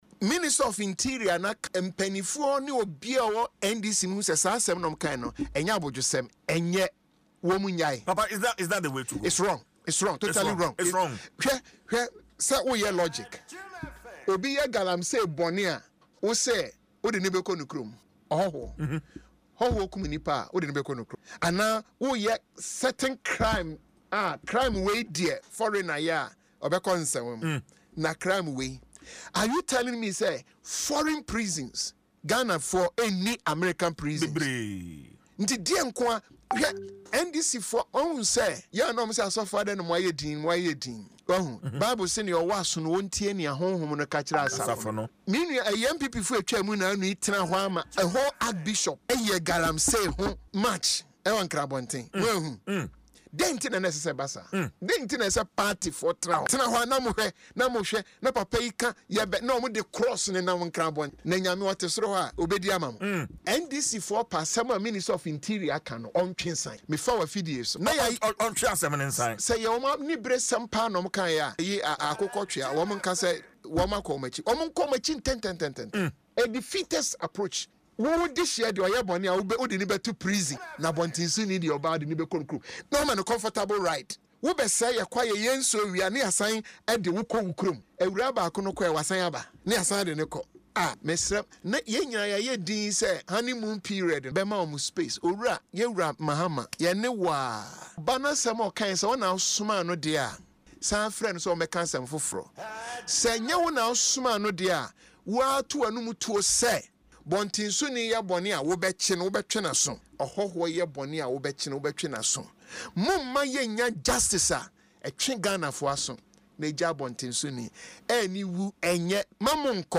Speaking in an interview on Adom FM’s morning show Dwaso Nsem, the outspoken clergyman described the decision as unfair and dangerous to Ghana’s justice system.